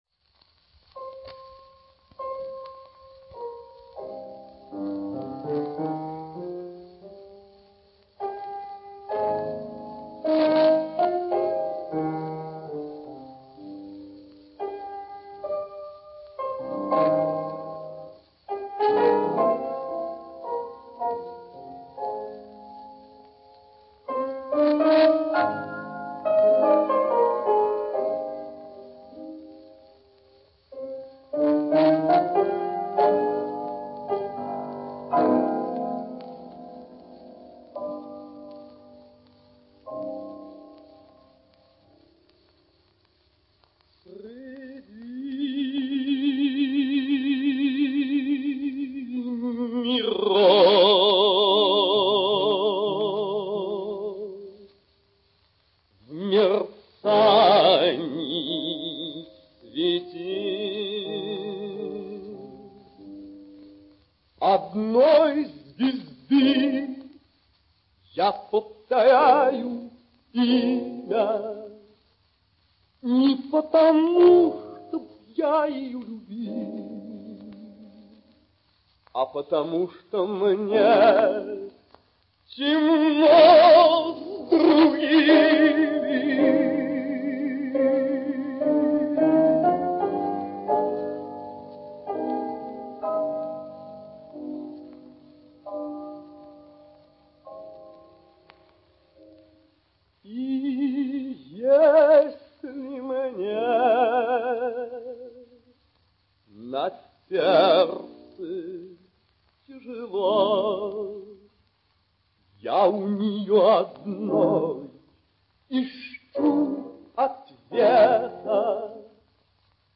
Грампластинка